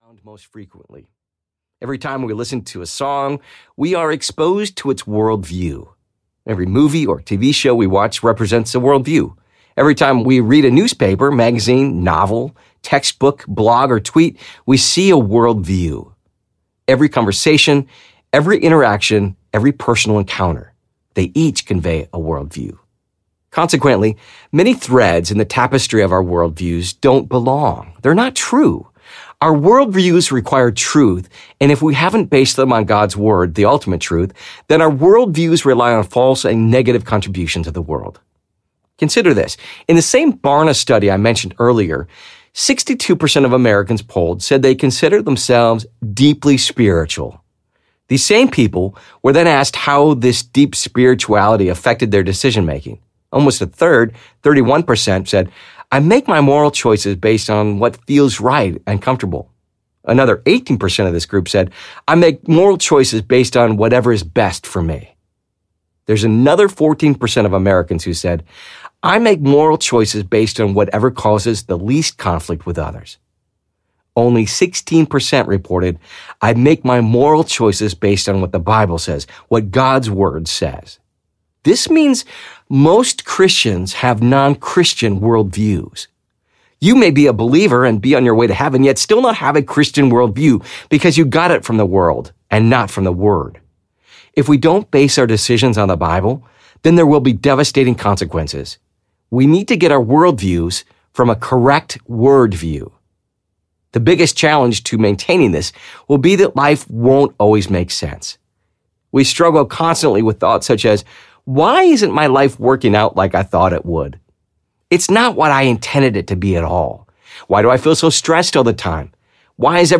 The Daniel Dilemma: How to Stand Firm and Love Well in a Culture of Compromise Audiobook